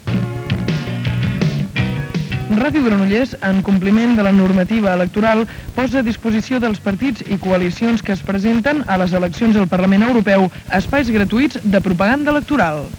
Anunci que es faran espais gratuïts de propaganda electoral amb motiu de les eleccions europees.
Informatiu
FM